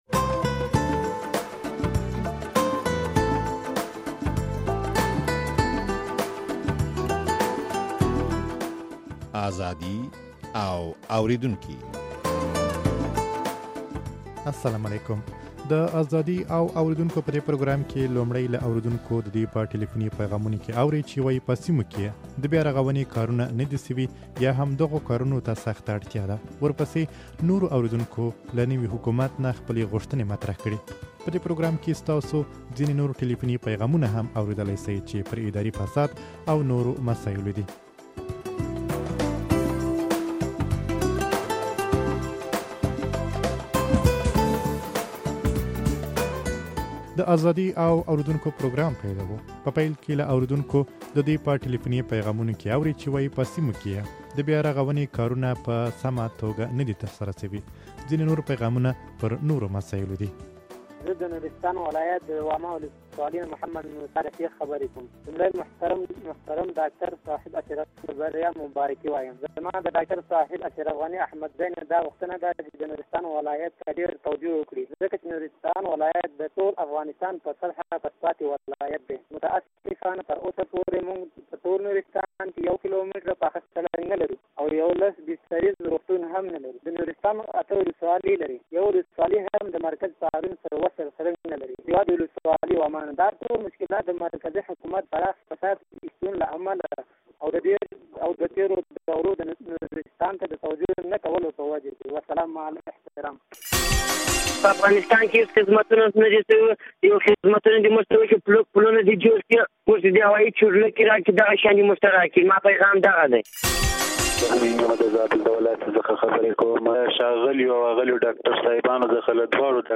د ازادي او اورېدونکو په دې پروګرام کې لومړى له اورېدونکو د دوى په ټليفوني پيغامونو کې اورئ چې وايي په سيمو کې يې د بيارغونې کارونه نه دي، يا هم دغو کارونو ته اړتيا ده.